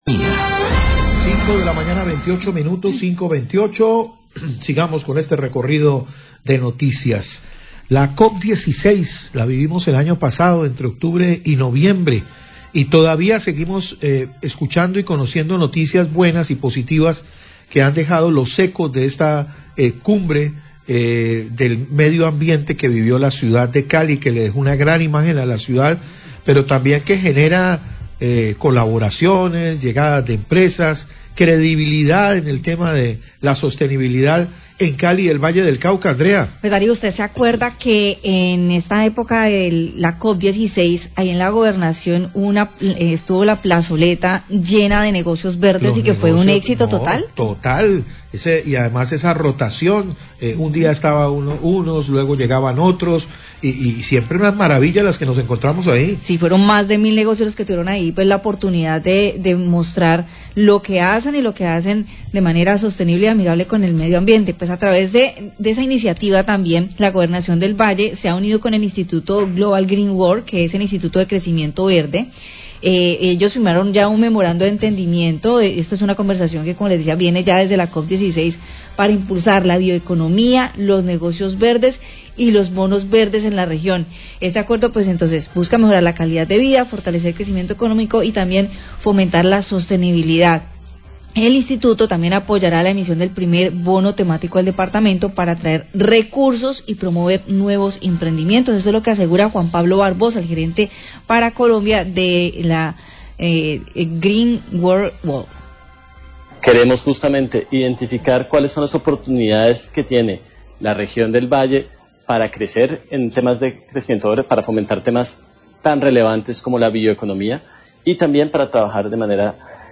Radio
Periodistas inician hablan del éxito de la pasada muestra de negocios verdes en la Plazoleta de San Francisco durate la COP16 e informan que la Gobernación del Valle se unió alInstituto Global Green World para impulsar la bioeconomia, los negocios verdes y los bonos veredes en la región.